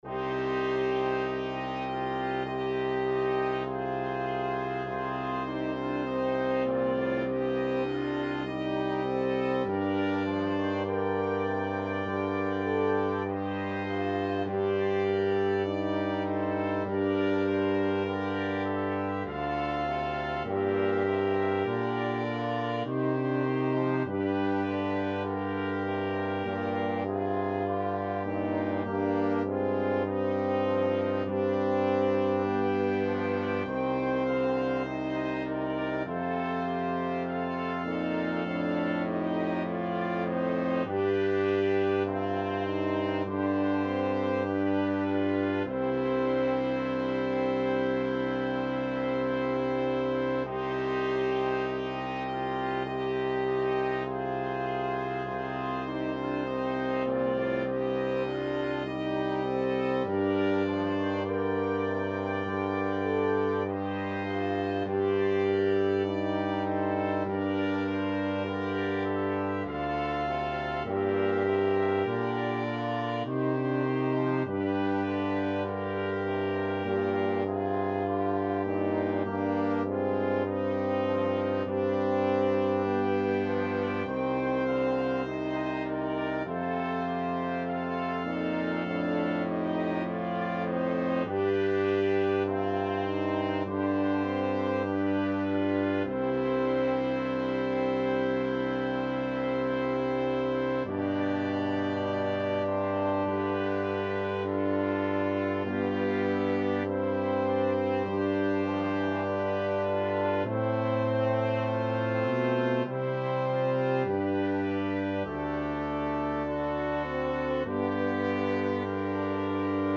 Brass Quintet version
Trumpet 1Trumpet 2French HornTromboneTuba
2/2 (View more 2/2 Music)
Classical (View more Classical Brass Quintet Music)